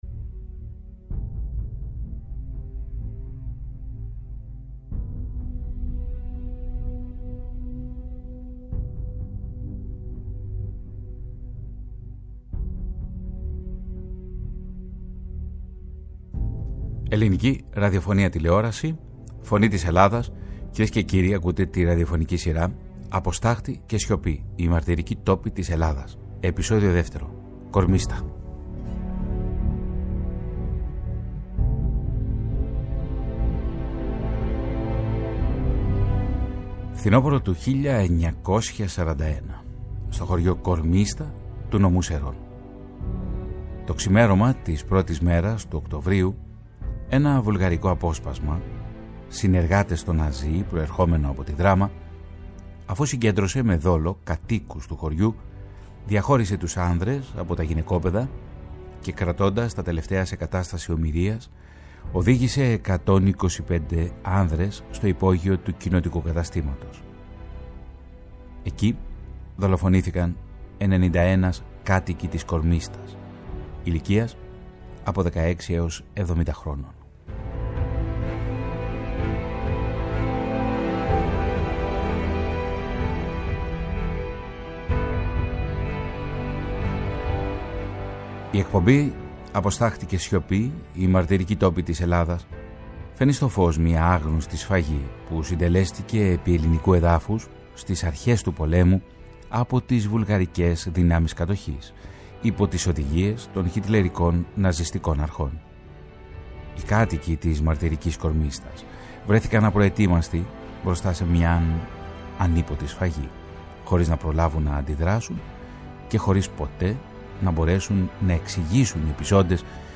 Ντοκιμαντέρ